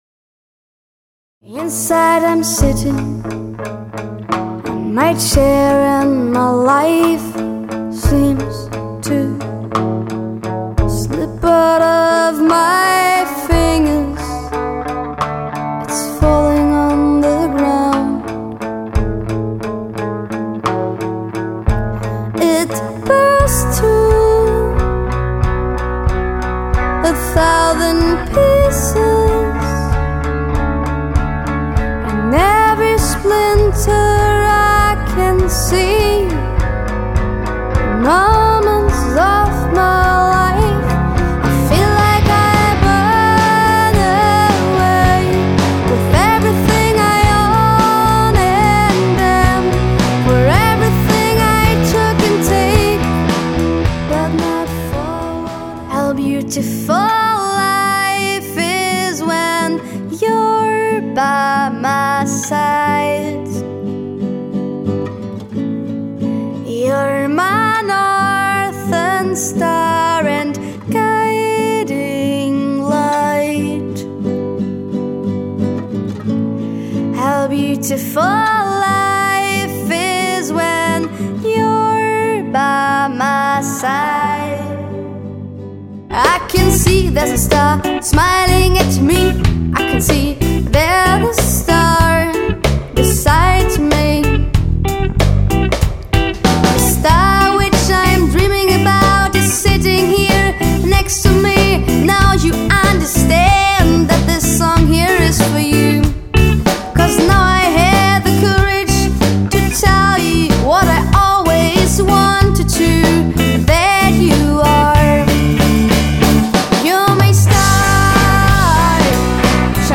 E-Gitarre, Gesang, Klavier
Drums